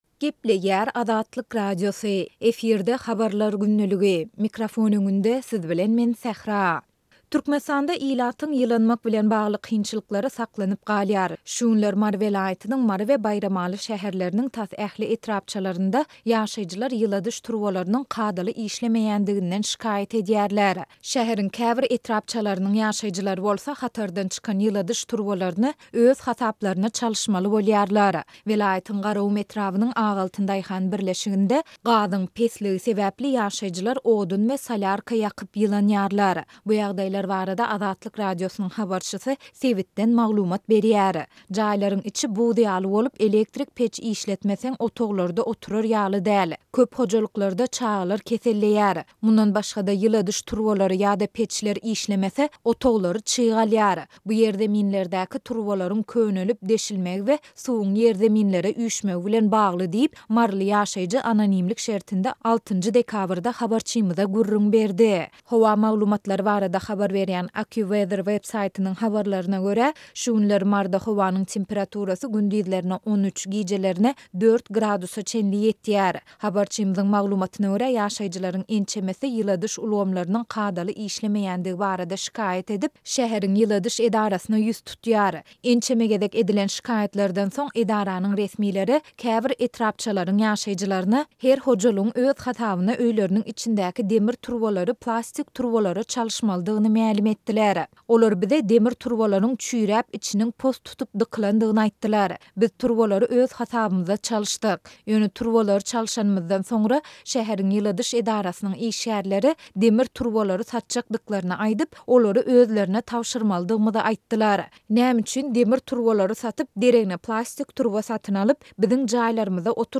Welaýatyň Garagum etrabynyň "Ak altyn" daýhan birleşiginde gazyň pesligi sebäpli ýaşaýjylar odun we salýarka ýakyp ýylynýarlar. Bu ýagdaýlar barada Azatlyk Radiosynyň habarçysy sebitden maglumat berýär.